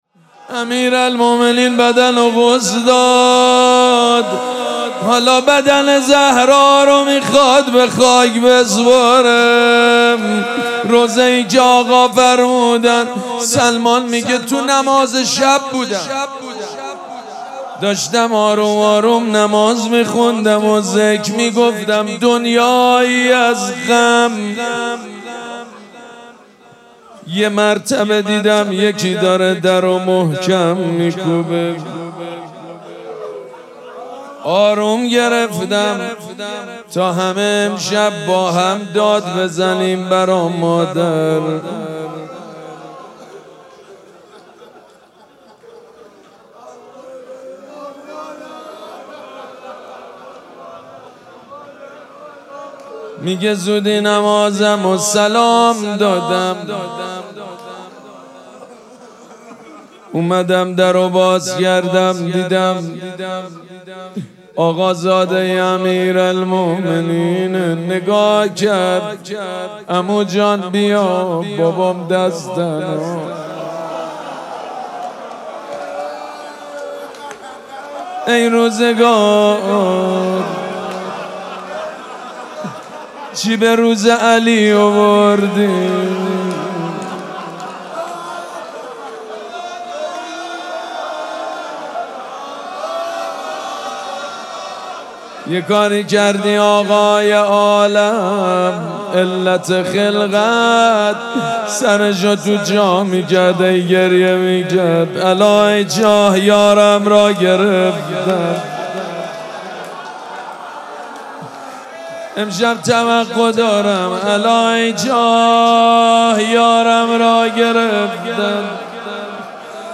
شب پنجم مراسم عزاداری دهه دوم فاطمیه ۱۴۴۶
روضه